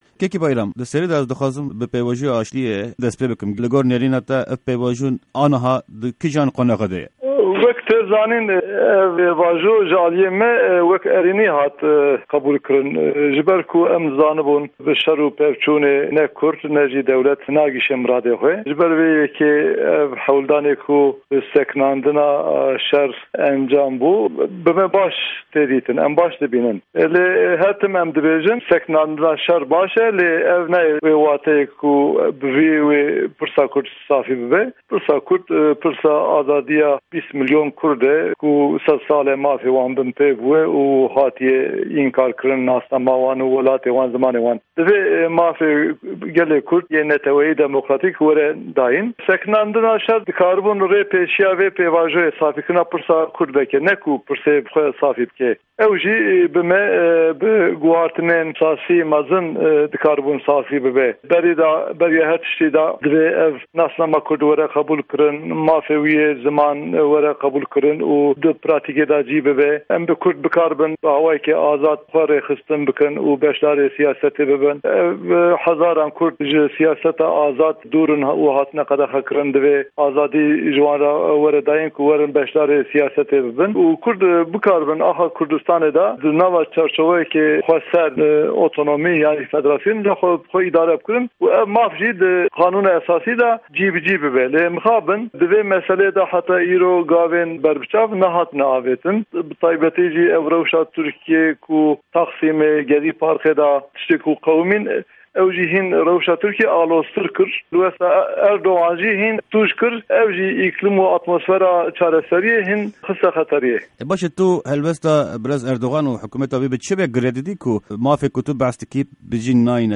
Di hevpeyvîna Dengê Amerîka de, Cîgirê Serokê Giştî yê Partîya Maf û Azadîyan (HAK-PAR) Bayram Bozyel ser pêvajoya aştîyê daxiyanîyên girîng dan.
Hevpeyvîn_Bayram_Bozyel